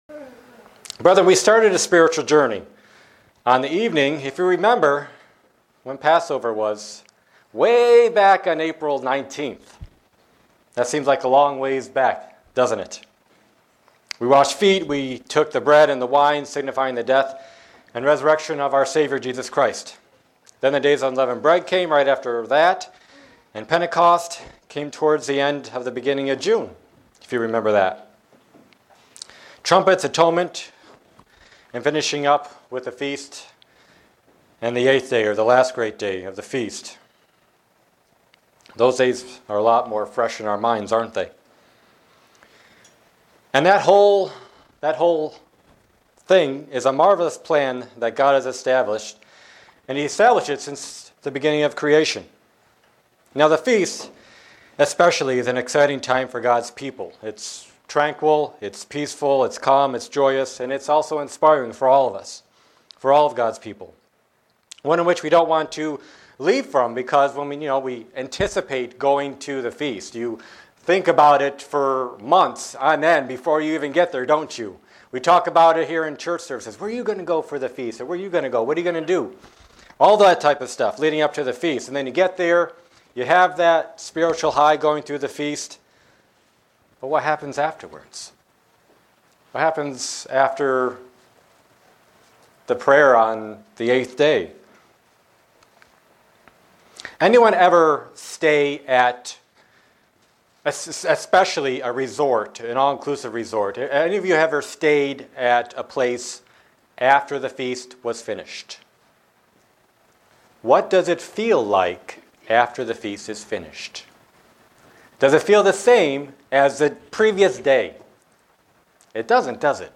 Print A number of ways to keep the excitement of the Feast alive. sermon Studying the bible?
Given in Buffalo, NY